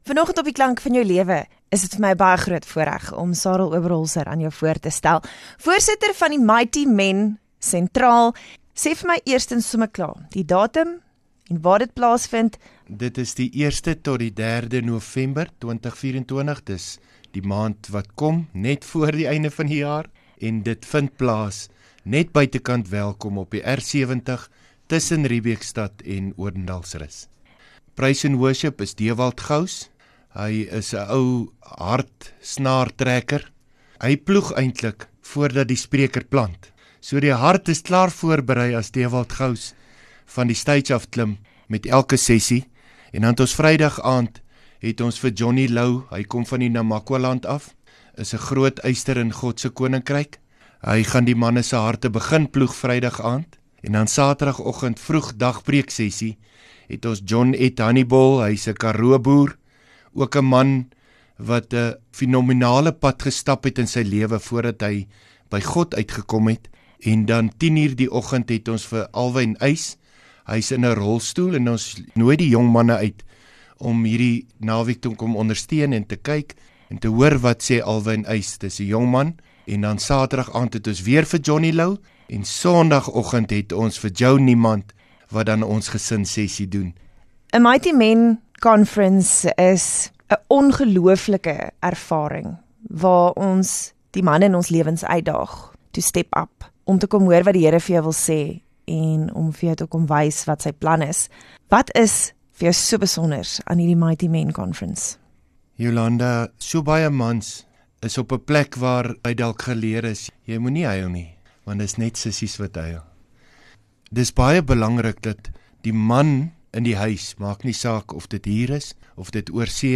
Die Mighty Men Conference Sentraal SA vind van Vrydag, 1 November tot Sondag, 3 November by Bundu Game Lodge, buite Welkom plaas. Luister hier na die onderhoud.